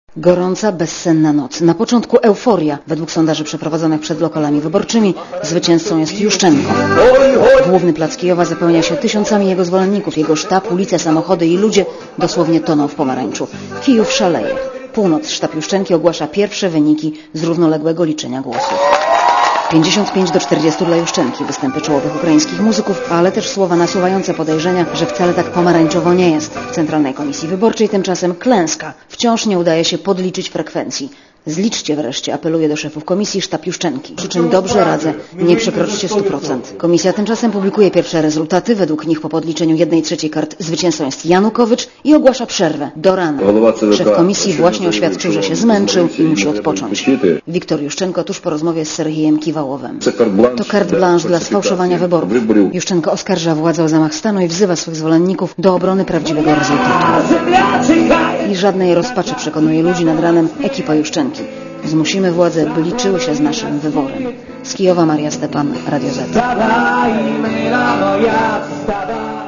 ukraina-noc_po_wyborach.mp3